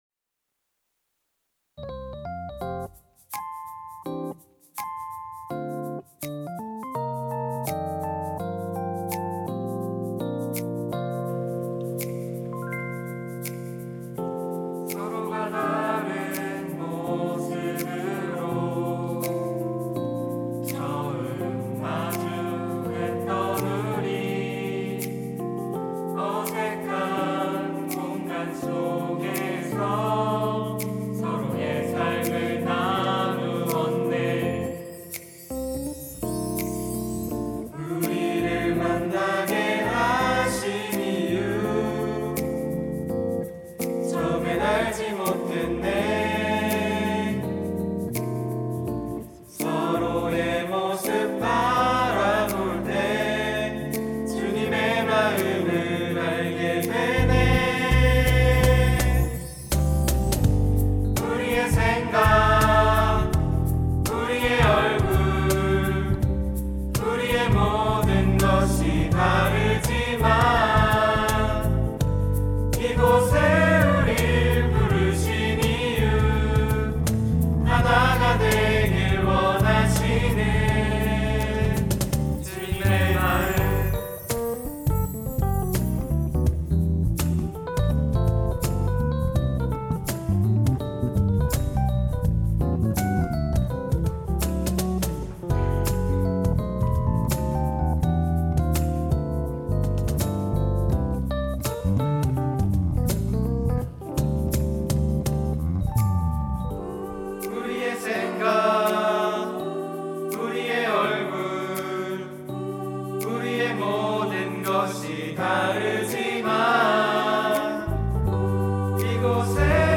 특송과 특주 - 우리
청년부 신혼부부셀